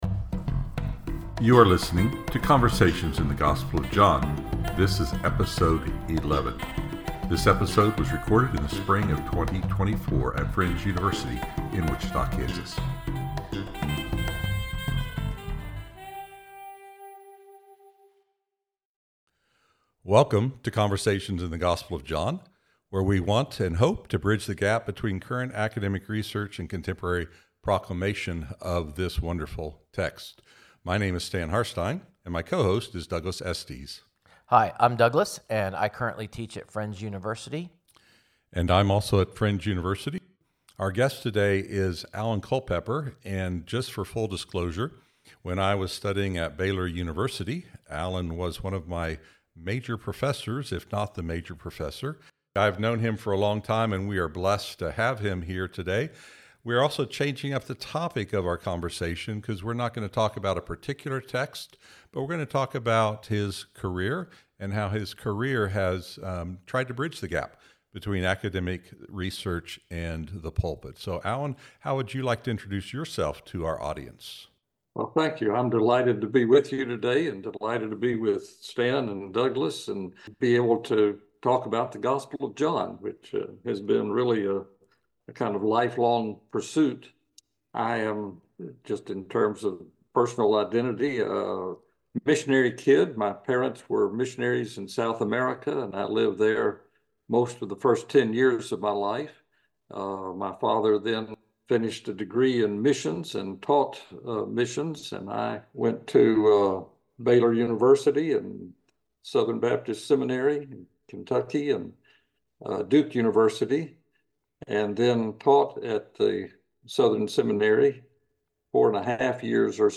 invite other Johannine scholars to discuss how their research can impact preaching and teaching.